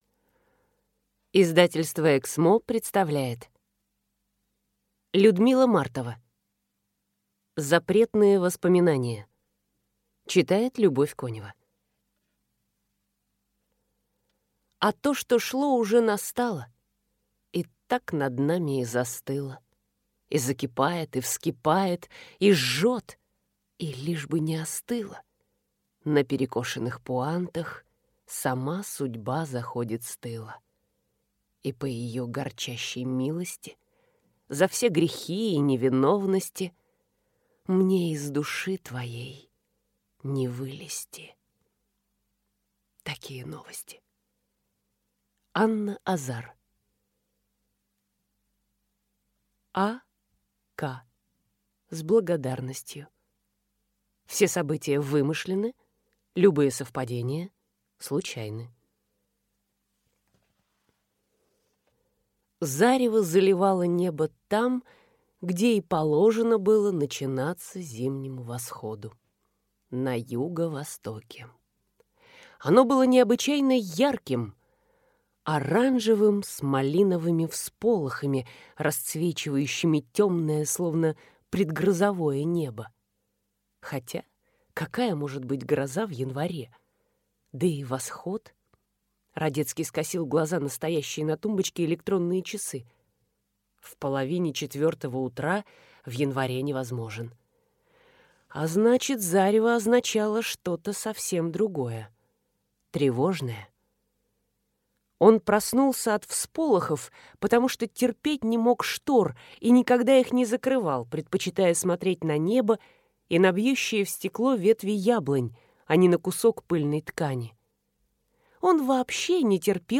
Аудиокнига Запретные воспоминания | Библиотека аудиокниг
Прослушать и бесплатно скачать фрагмент аудиокниги